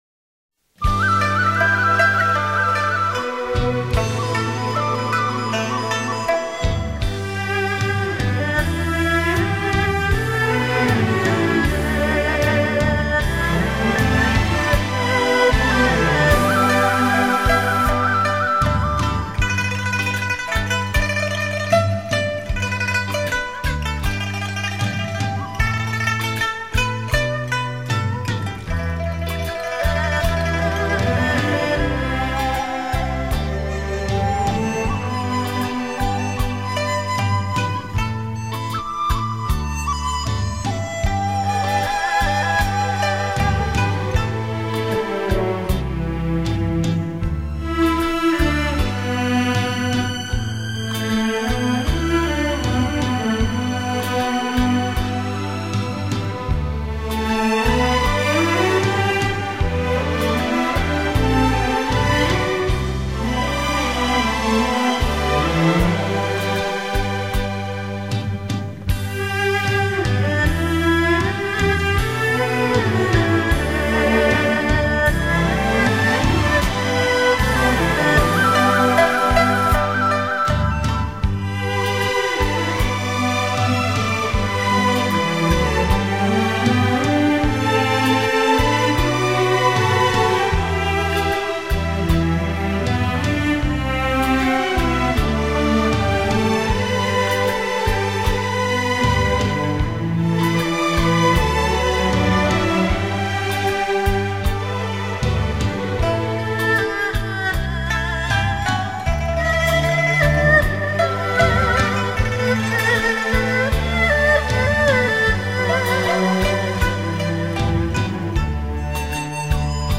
丝竹情韵 《心声泪痕》